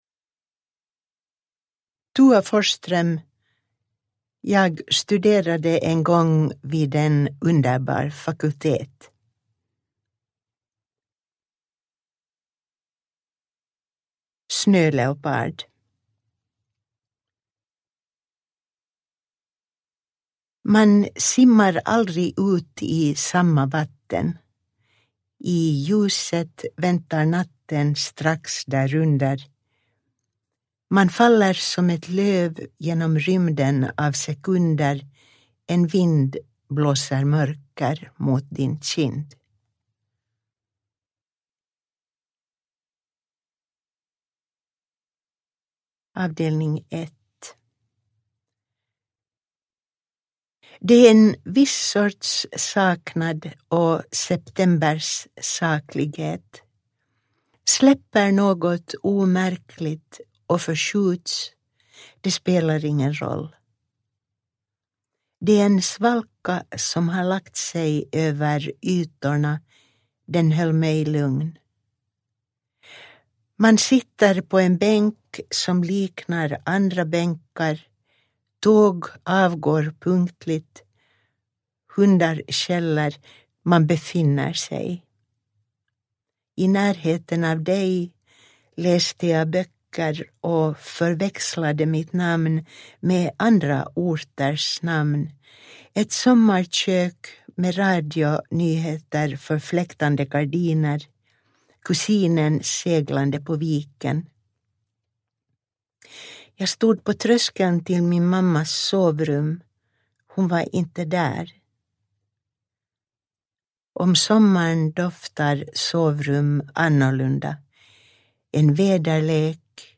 Jag studerade en gång vid en underbar fakultet – Ljudbok – Laddas ner
Jag studerade en gång vid en underbar fakultet (första gången utgiven 2003) innehåller samlingarna Snöleopard (1987), Parkerna (1992), Efter att ha tillbringat en natt bland hästar (1997) och diktsviten Mineraler (2003).Uppläst av författaren Tua Forsström.
Uppläsare: Tua Forsström